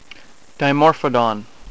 Pronunciation Key
di-MORF-o-dawn